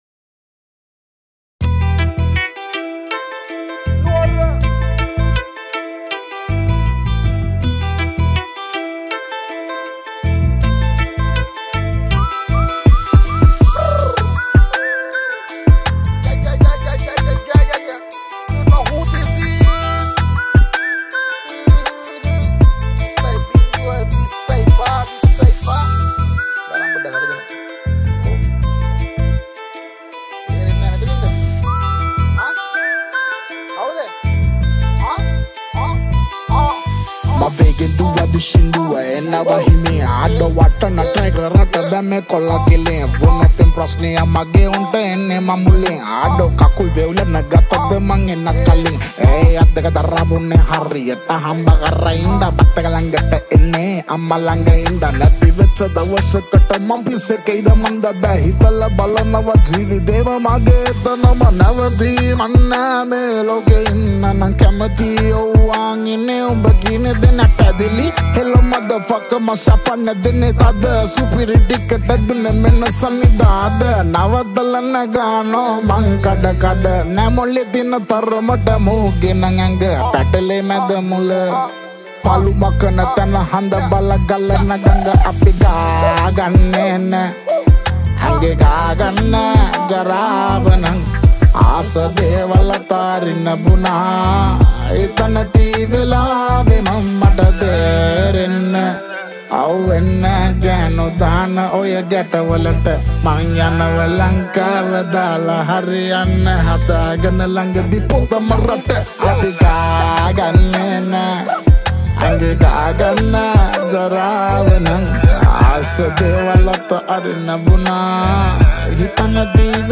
Releted Files Of Sinhala New Rap Mp3 Songs